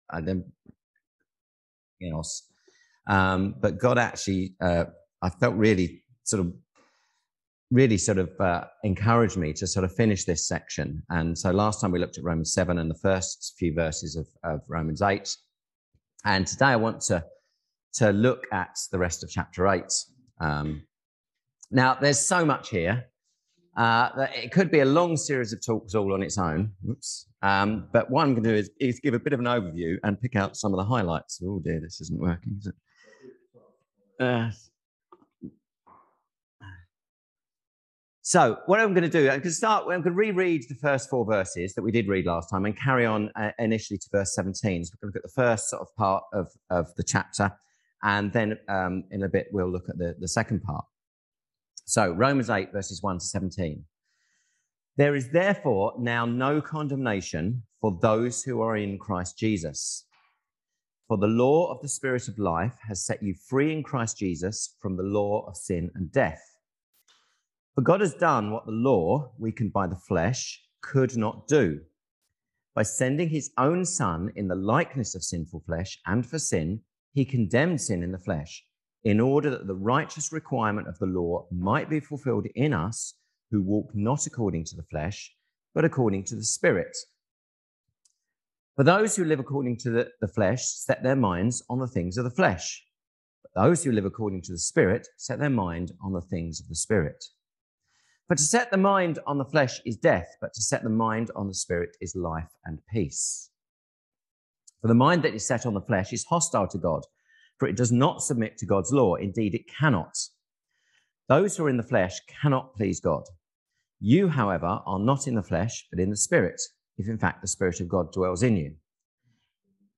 Passage: Romans 8 Service Type: Sunday Service Topics